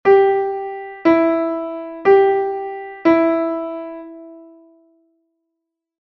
Listen to the sound file and write the notes you hear (E or G).
e_g_4_notes.1.mp3